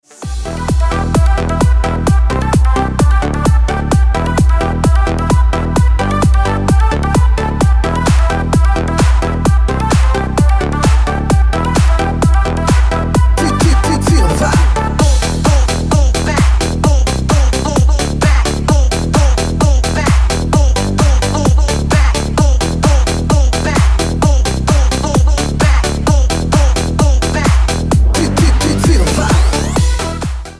Клубные рингтоны
New eurodance